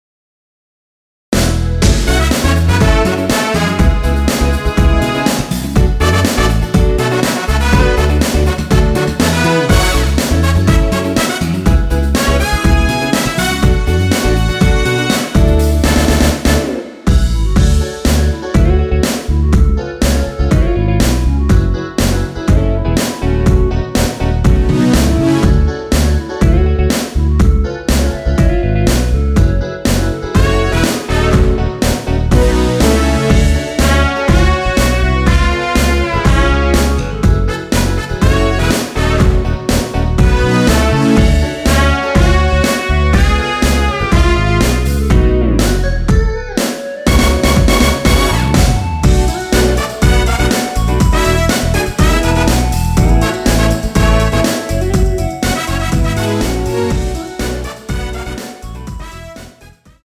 원키에서(-1)내린 멜로디 포함된 MR입니다.
F#m
앞부분30초, 뒷부분30초씩 편집해서 올려 드리고 있습니다.
(멜로디 MR)은 가이드 멜로디가 포함된 MR 입니다.